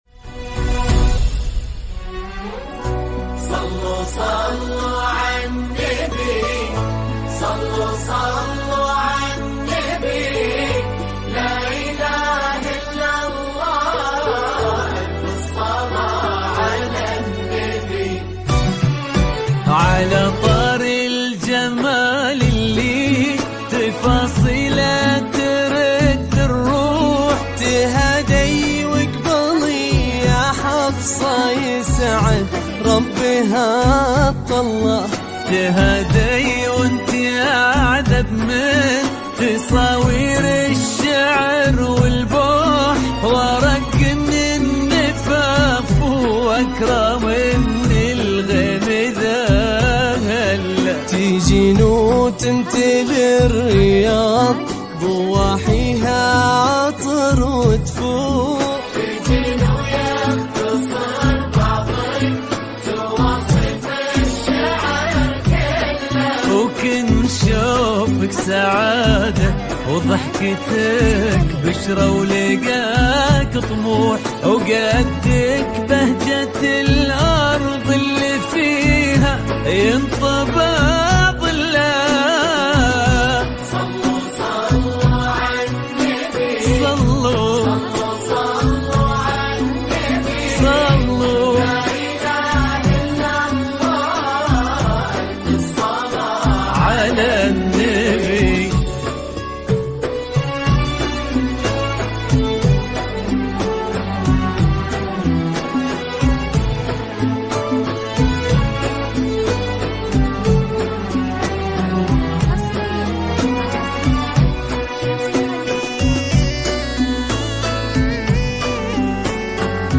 زفات دفوف – زفات معرس